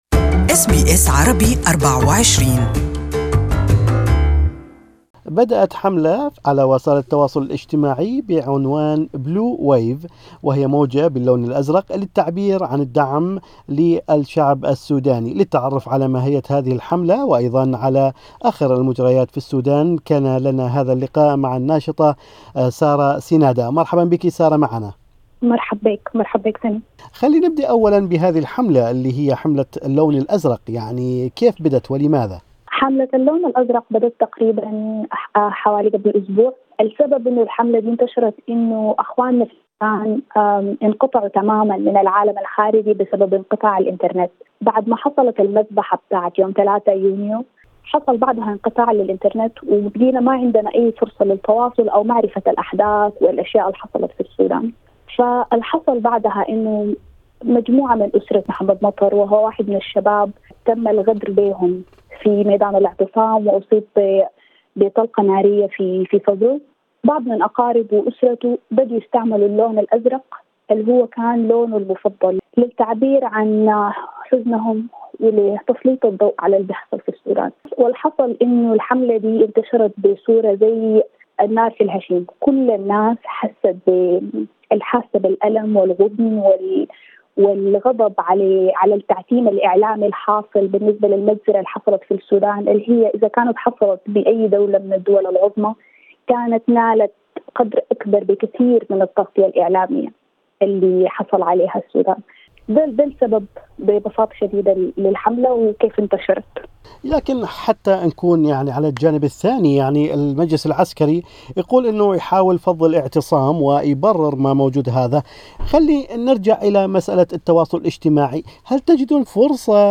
مقابلة مع اذاعة أس بي اس عربي 24